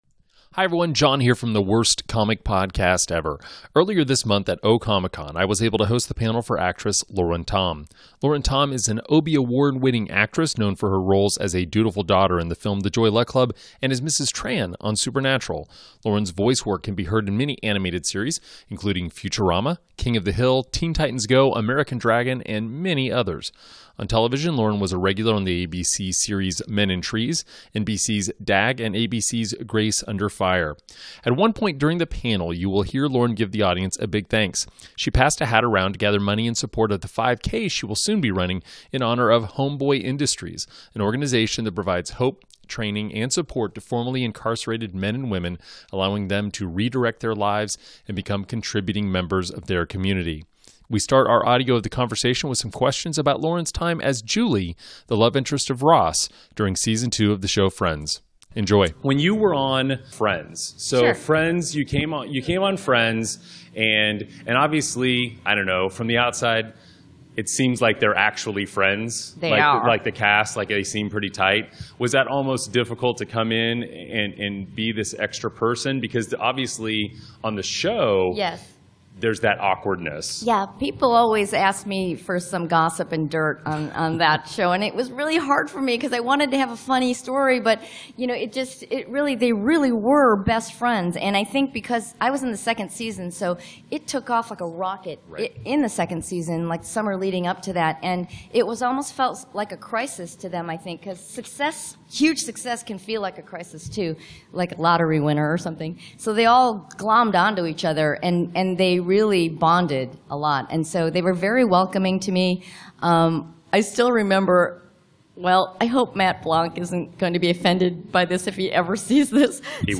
Panel with Actress Lauren Tom – O Comic Con 2016
Earlier this month at O Comic Con, I was able to host the panel for actress Lauren Tom.
lauren-tom-occ-panel-with-intro.m4a